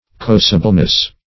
Search Result for " corrosibleness" : The Collaborative International Dictionary of English v.0.48: Corrosibleness \Cor*ro"si*ble*ness\, n. The quality or state of being corrosible.
corrosibleness.mp3